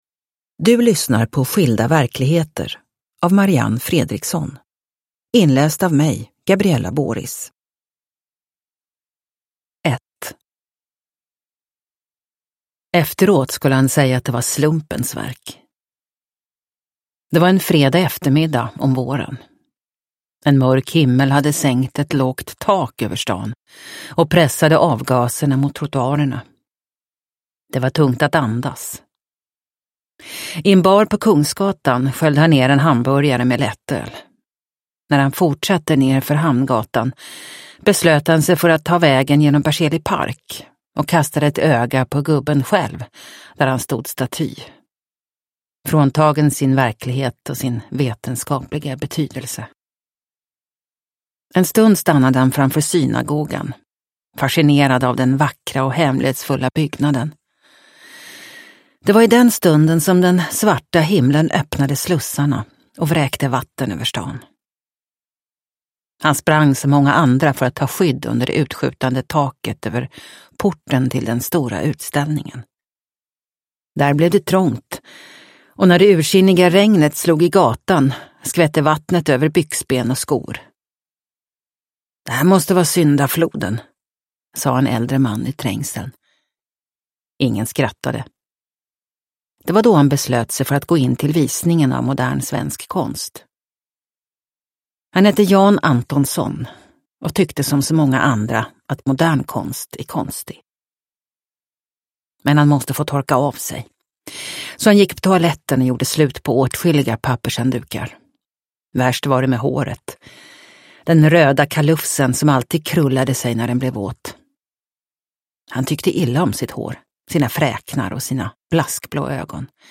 Skilda verkligheter – Ljudbok – Laddas ner
Uppläsare: